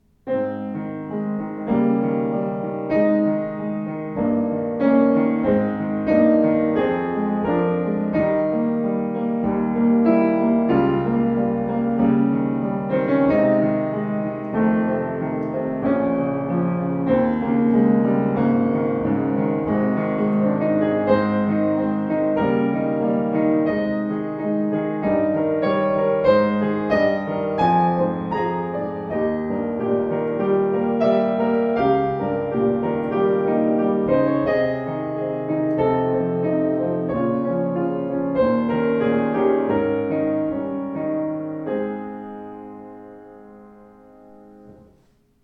Klavier Yamaha U3 schwarz
demnächst wieder verfügbar: U3 mit vollem, konzertantem Klang in schwarz poliert